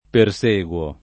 perseguire v.; perseguo [